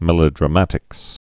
(mĕlə-drə-mătĭks)